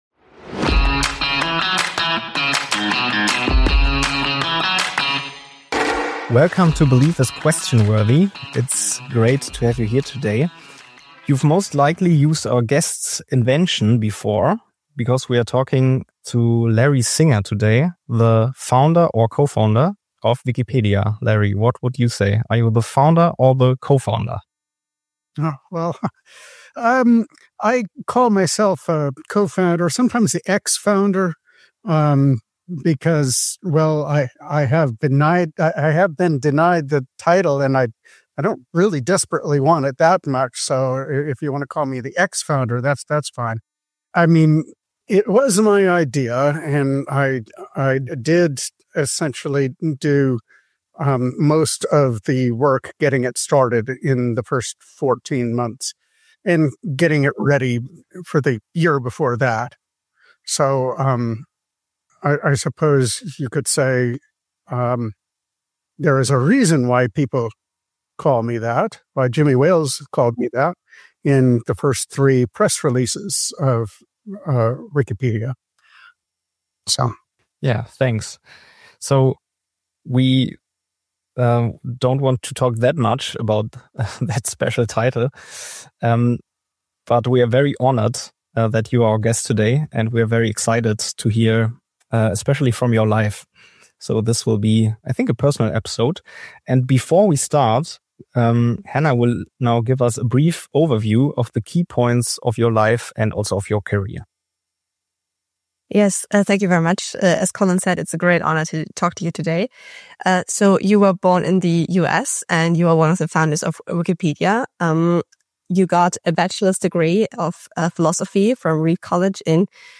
- A Conversation with Lawrence Mark “Larry” Sanger (Co-Founder of Wikipedia) ~ Glaube ist frag-würdig Podcast